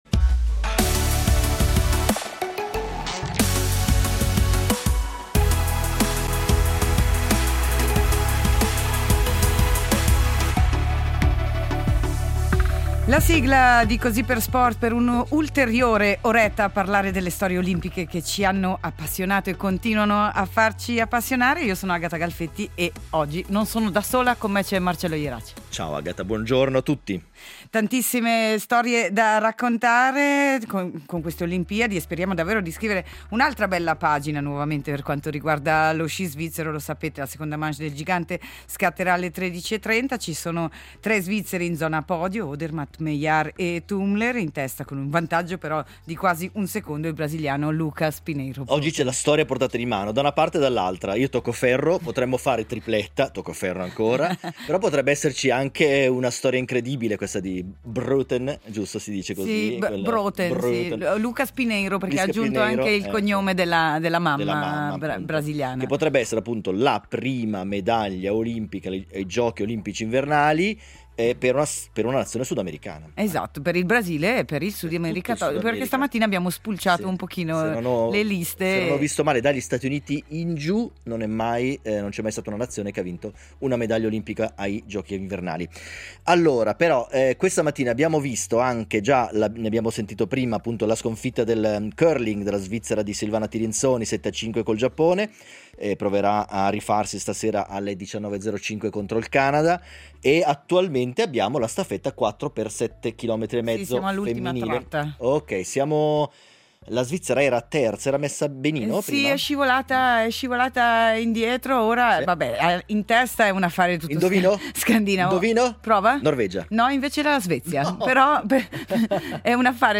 Tornano le Olimpiadi e torna Così per sport per raccontarvi minuto per minuto i Giochi di Milano-Cortina. I risultati, il medagliere, le voci degli inviati e le storie degli ospiti che hanno vissuto sulla propria pelle la rassegna a cinque cerchi, ma anche i dietro le quinte e le curiosità delle varie discipline per immergerci tutti insieme – sportivi e meno – nello spirito olimpico.